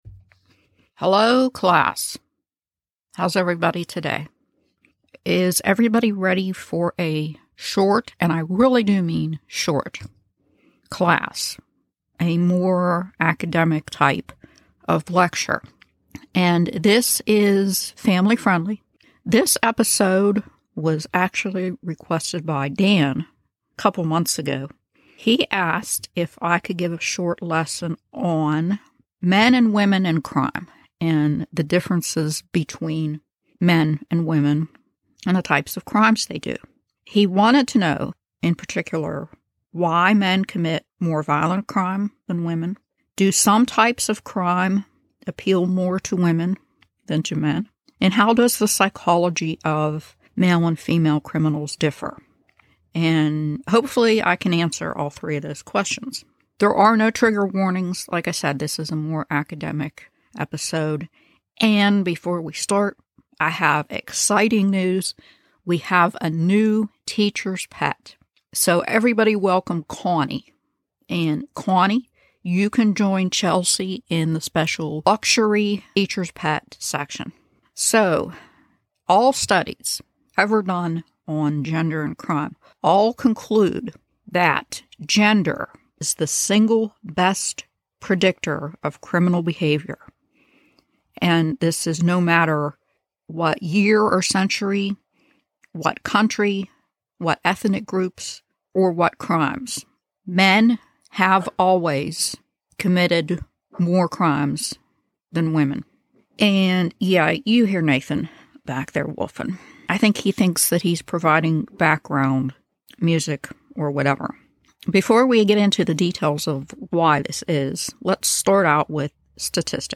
Marriage, Horror, Lifestyle, Halloween, Crime, Husband, Couple, Comedy, True Crime, Society & Culture, Satire, Murder, Wife